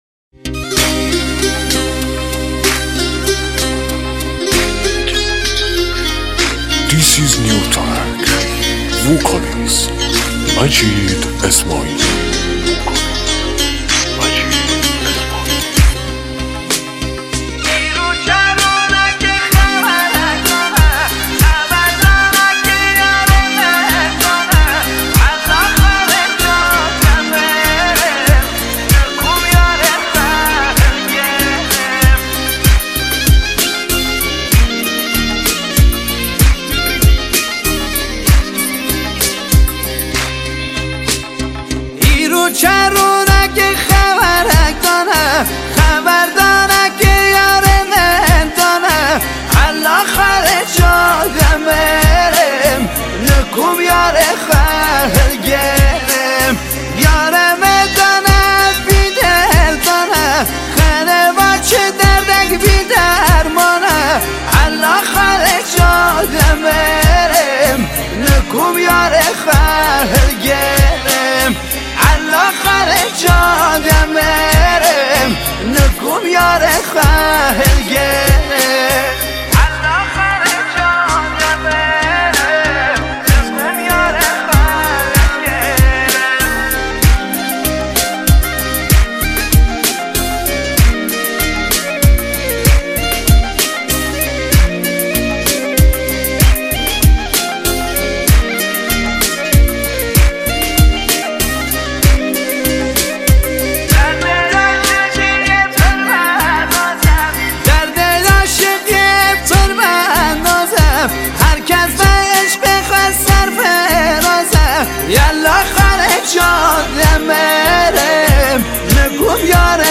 آهنگ مشهدی